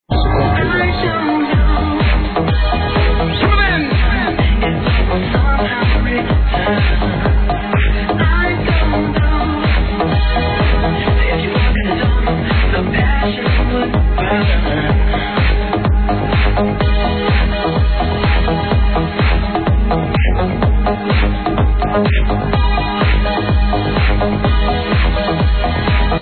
old vocal track id needed
Ibelieve that this track is 2 or more years old...anyone know the title?when you listen to it don't make attention to the "speaker"!